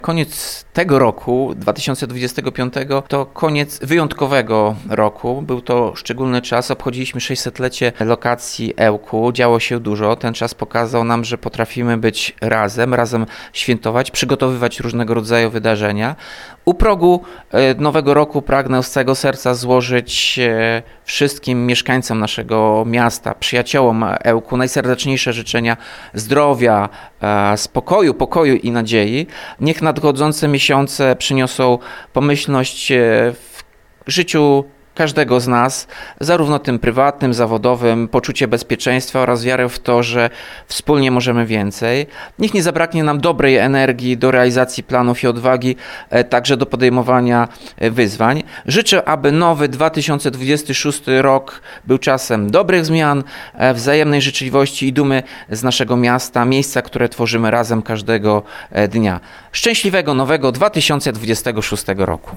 Życzenia noworoczne prezydenta Ełku - Radio5
Prezydent Ełku Tomasz Andrukiewicz życzy mieszkańcom, gościom, a także wszystkim przyjaciołom Ełku, by rok 2026 był pełen zdrowia, szczęścia i nadziei.